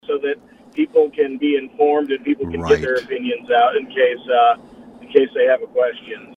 (Audubon) The Audubon County Board of Supervisors held another public hearing to amend the zoning ordinance for the Apex Energy wind turbine project between Brayton and Elk Horn. Audubon County Board of Supervisor Chairman Heath Hanson says the supervisors must hold a series of public hearings before officially changing the ordinance.